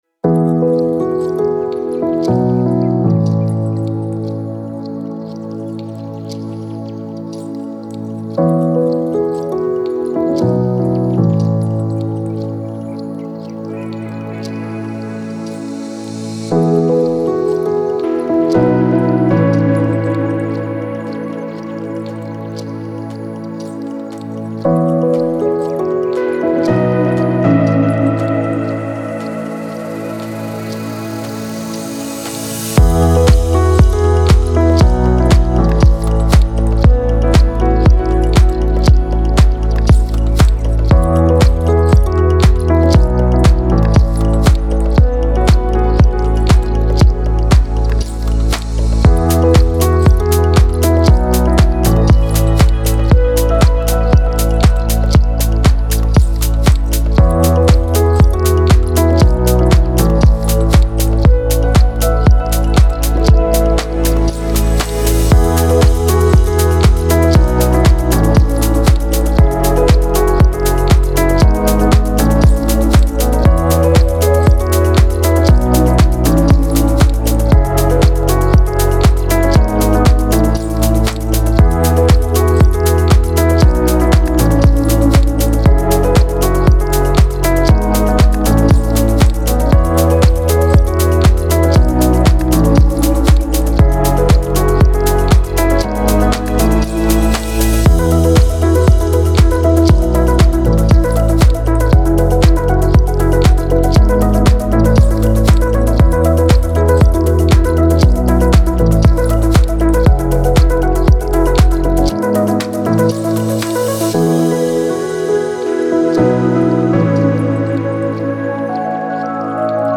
ریتمیک آرام
موسیقی بی کلام چیل اوت